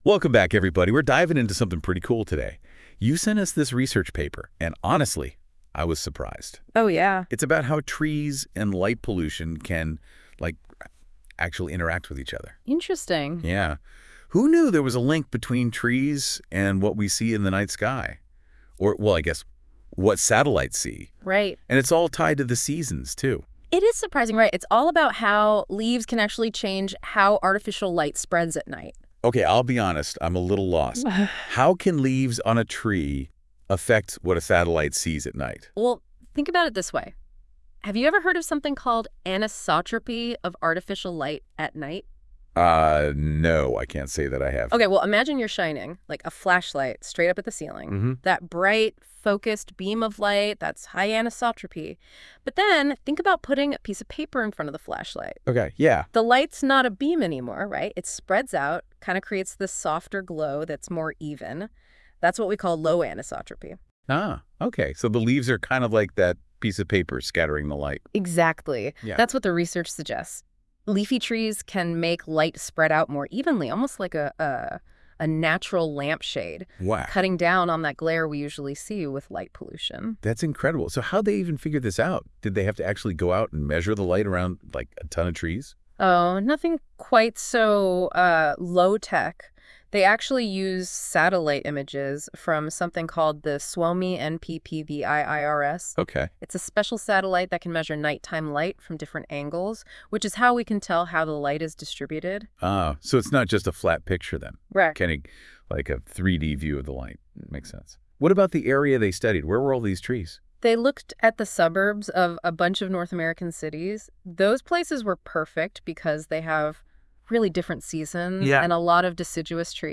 音频内容由AI自动生成，仅供参考。